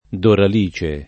vai all'elenco alfabetico delle voci ingrandisci il carattere 100% rimpicciolisci il carattere stampa invia tramite posta elettronica codividi su Facebook Doralice [ doral &© e ] pers. f. — personaggio di L. Ariosto